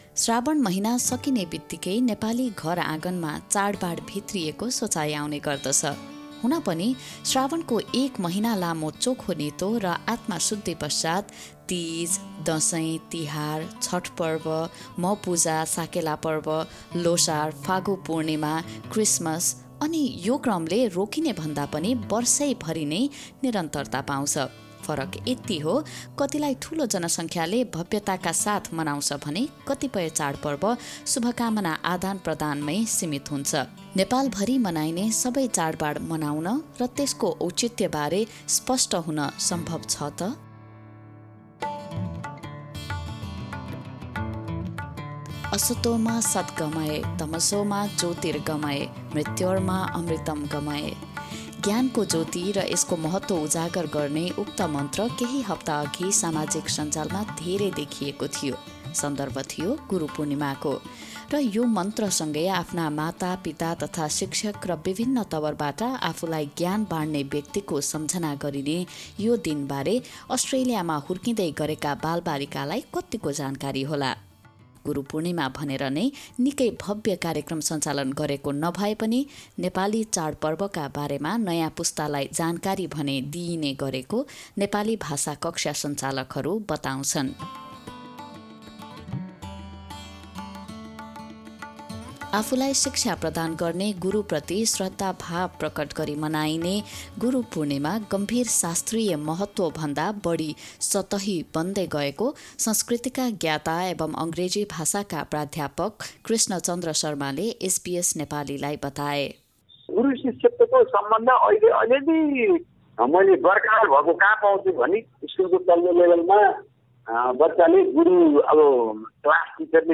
एक विशेष रिपोर्ट।